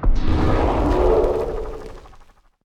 prepare_attack2.wav